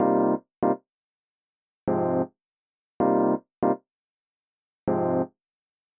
描述：混乱的贝斯声
Tag: 低音 敷于